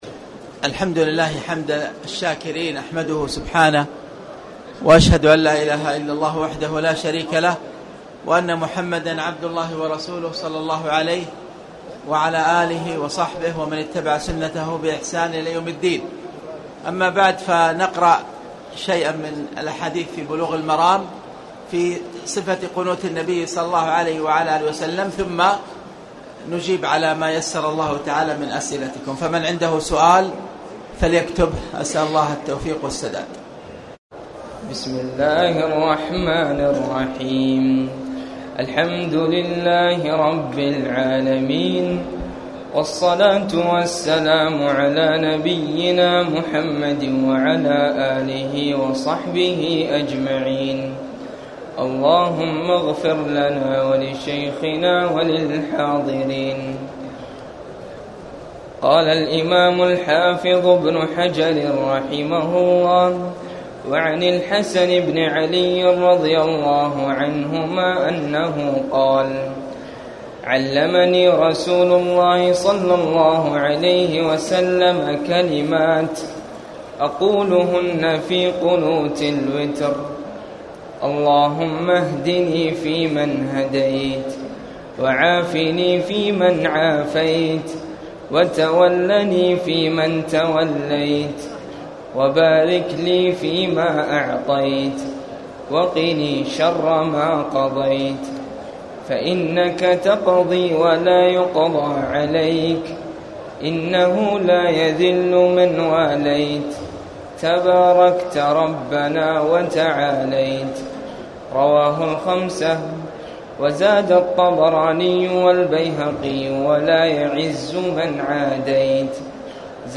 تاريخ النشر ٢٧ رمضان ١٤٣٨ هـ المكان: المسجد الحرام الشيخ: فضيلة الشيخ أ.د. خالد بن عبدالله المصلح فضيلة الشيخ أ.د. خالد بن عبدالله المصلح باب صفة الصلاة